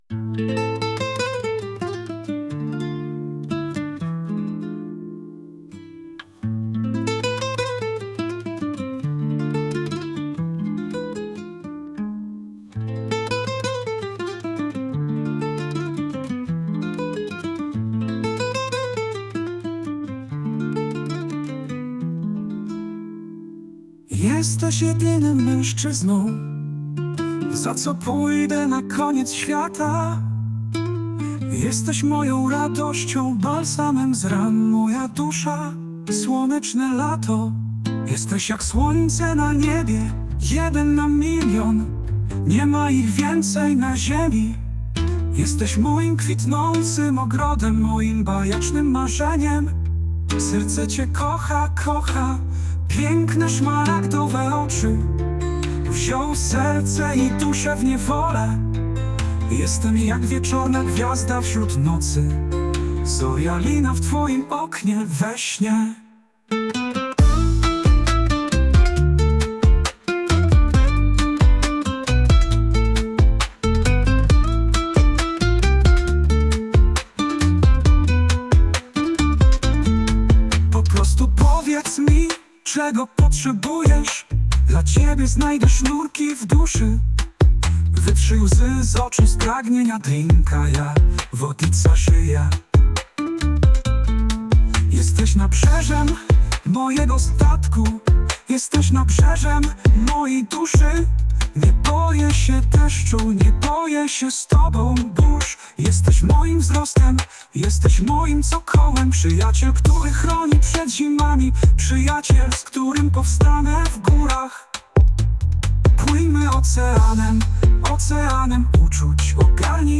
Романс (польською мовою)
романс.mp3